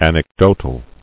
[an·ec·dot·al]